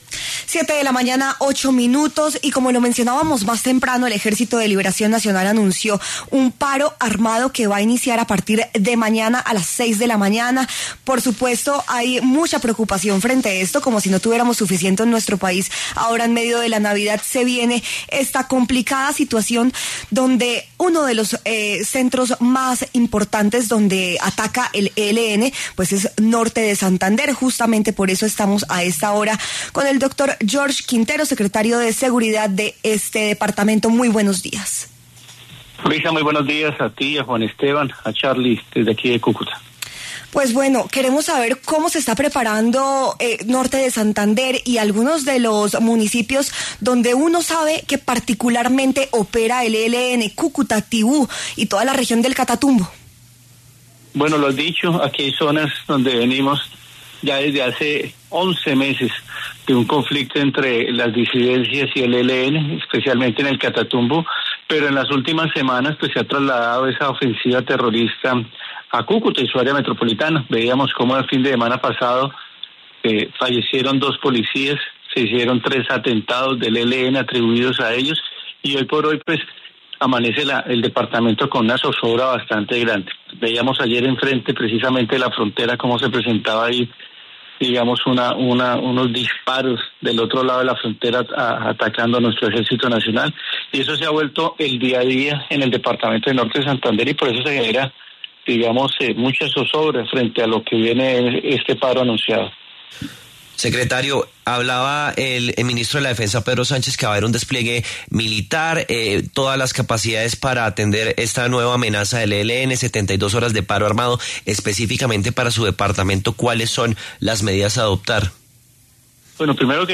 El secretario de Seguridad de Norte de Santander, George Quintero, pasó por los micrófonos de W Fin De Semana para hablar acerca del anuncio del Ejército de Liberación Nacional (ELN) de un paro armado de 72 horas a nivel nacional.